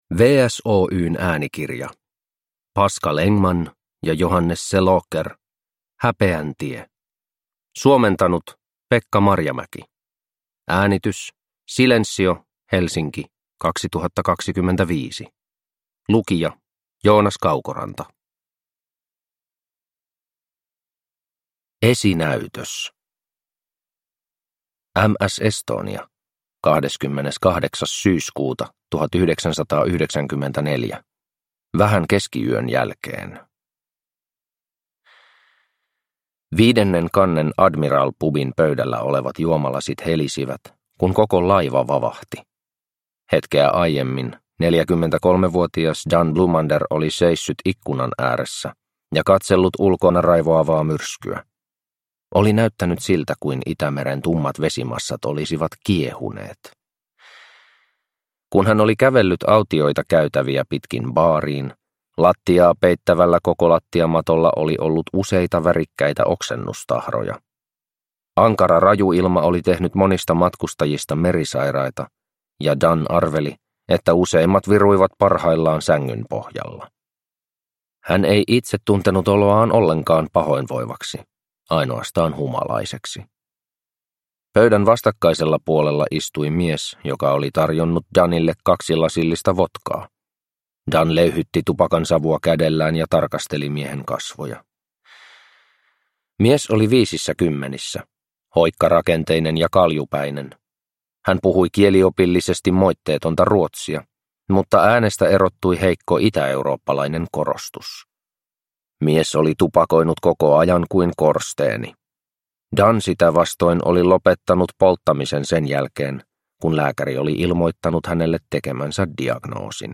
Häpeän tie (ljudbok) av Pascal Engman